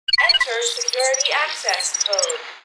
passcoderequired.ogg